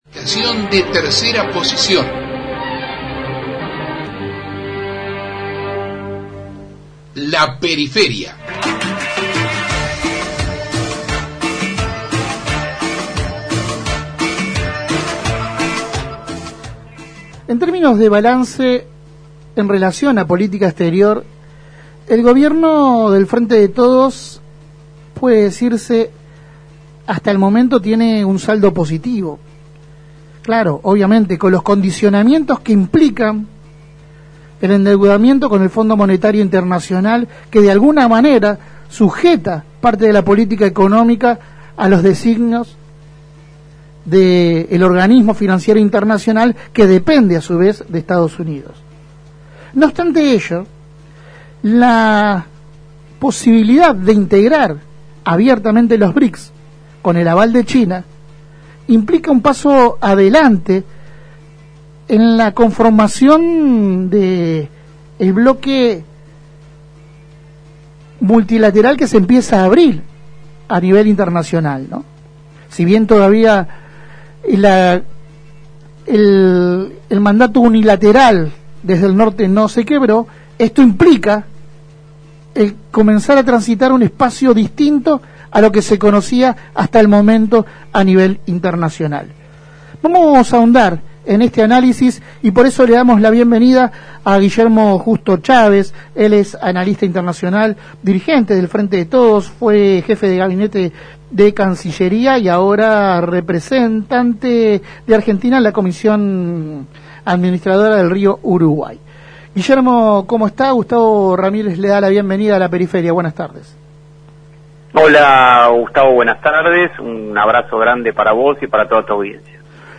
En La Periferia dialogamos con Guillermo Justo Cháves, analista internacional, dirigente del Frente de Todos, ex jefe de Gabinete de Cancillería y ahora representante de Argentina en la Comisión Administradora del Rio Uruguay, quien analizó la política internacional del gobierno.
Compartimos la entrevista completa: